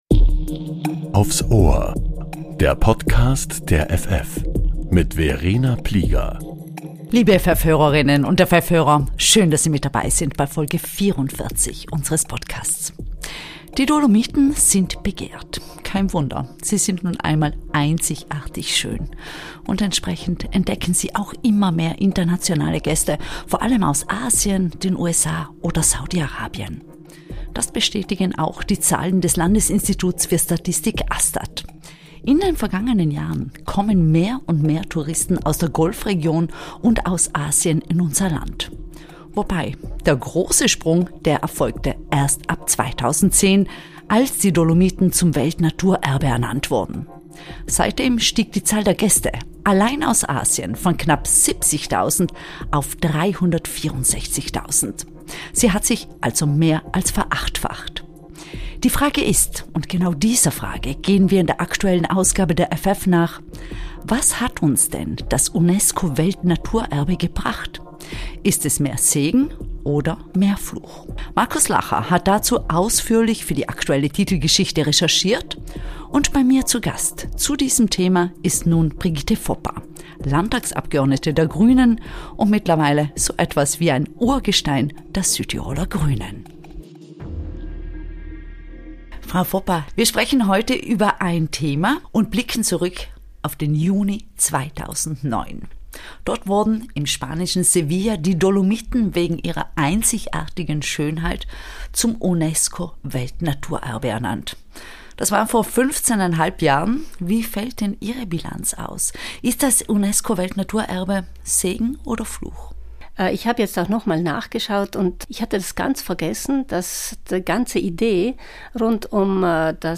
Brigitte Foppa, Landtagsabgeordnete der Grünen, spricht über das Unesco Weltnaturerbe: Ist es ein Segen oder ein Fluch für die Dolomiten?